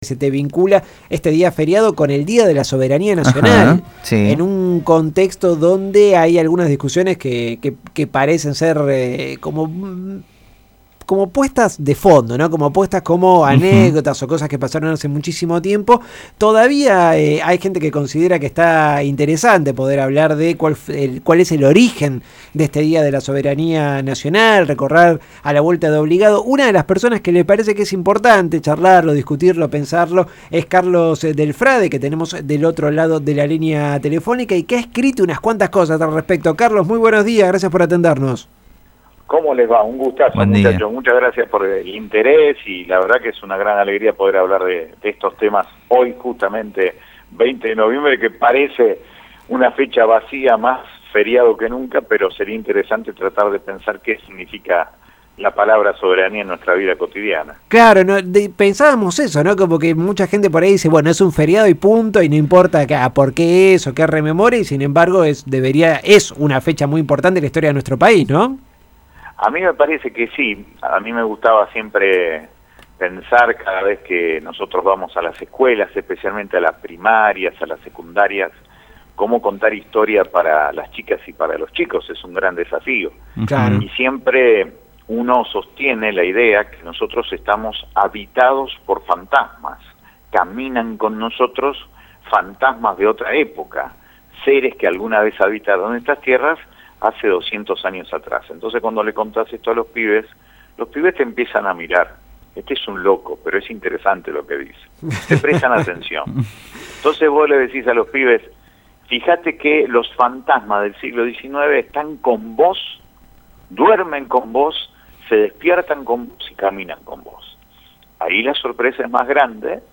Escuchá la entrevista completa: Carlos del Frade – Descargar audio Radio Estación Sur Etiquetas: Carlos del Frade, Día de la Soberanía Nacional